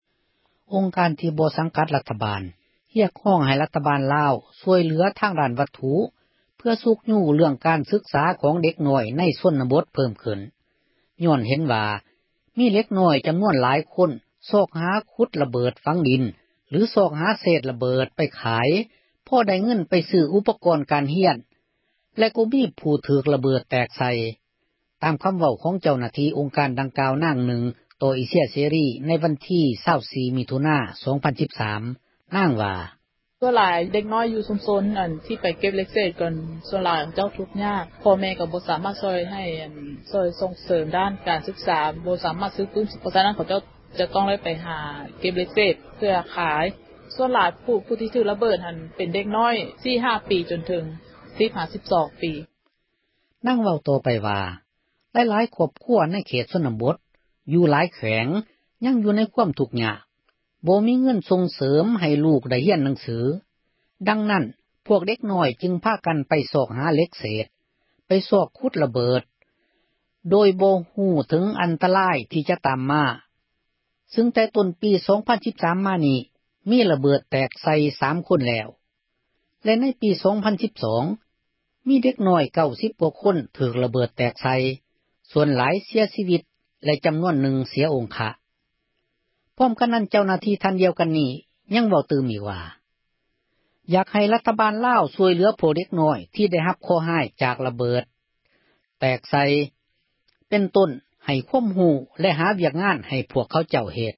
ອົງການ ທີ່ ບໍ່ສັງກັດ ຣັຖບາລ ຮຽກຮ້ອງ ໃຫ້ ຣັຖບາລ ລາວ ຊ່ວຍເຫລືອ ດ້ານວັດຖຸ ເພື່ອຊຸກຍູ້ ການສຶກສາ ຂອງ ເດັກນ້ອຍ ໃນ ຊົນນະບົດ ເພີ່ມຂຶ້ນ ຍ້ອນເຫັນວ່າ ມີເດັກນ້ອຍ ຈໍານວນ ຫລວງຫລາຍ ພາກັນ ຊອກຫາ ຂຸດຣະເບີດ ຝັງດິນ ຫລື ຊອກຫາ ເສດ ຣະເບີດ ໄປຂາຍ ພໍໄດ້ເງິນ ໄປຊື້ ອຸປກອນ ການຮຽນ ແລະ ກໍມີຜູ້ ທີ່ ຖືກ ຣະເບີດ ແຕກໃສ່. ຕາມຄໍາເວົ້າ ຂອງ ເຈົ້າໜ້າທີ່ ອົງການ ນາງນຶ່ງ ຕໍ່ ເອເຊັຍ ເສຣີ ໃນວັນທີ 24 ມິຖຸນາ 2013.